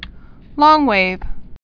(lôngwāv, lŏngwāv)